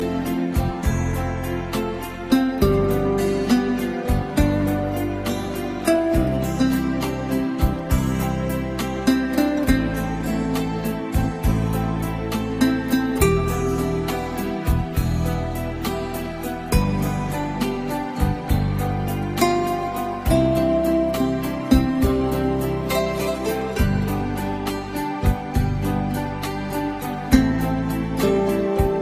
With its lyrical, deep melody and emotional vocals.
Ringtone
Blues , Rnb soul